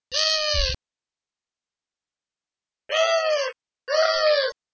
Streptopelia decaocto - Collared dove - Tortora dal collare
- IDENTIFICATION AND BEHAVIOUR: The male of a couple was performing the courtship display.
The male vocalizes while pursuing her. - POSITION: Poderone near Magliano in Toscana, LAT.N 42° 36'/LONG.E 11° 17'- ALTITUDE: +130 m. - VOCALIZATION TYPE: excited call.
- COMMENT: The doves are cached by the tree foliage while pursuing each other; it is not clear if all the calls are uttered by the male alone.